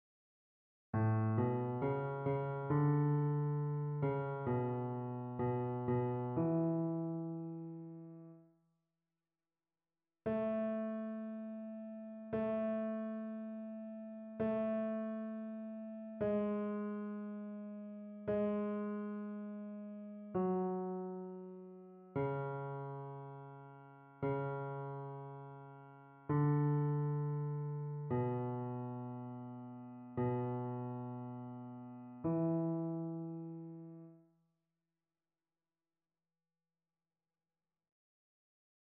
Basse
annee-abc-temps-du-careme-messe-christmale-psaume-88-basse.mp3